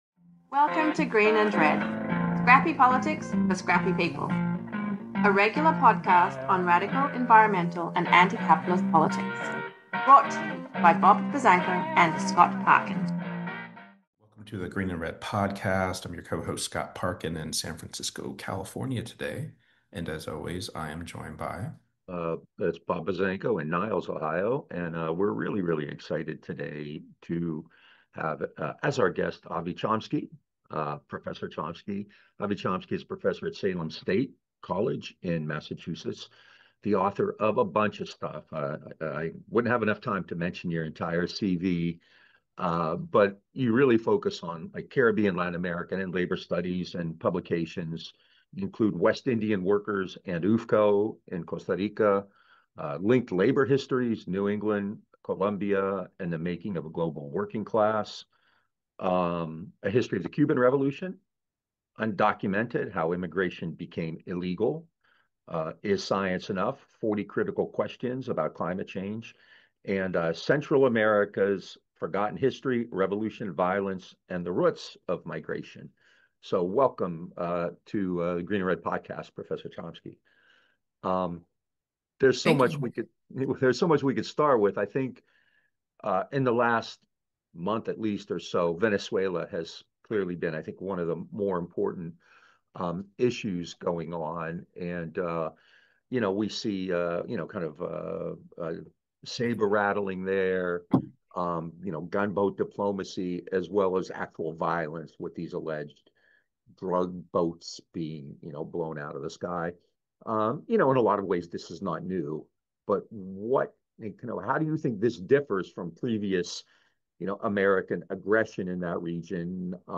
In a wide-ranging discussion w